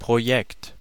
Ääntäminen
Synonyymit Konzept Plan Absicht Vorhaben Unternehmen Anlage Ääntäminen : IPA: [proˈjɛkt] Haettu sana löytyi näillä lähdekielillä: saksa Käännös Ääninäyte Substantiivit 1. project Canada US Canada Artikkeli: das .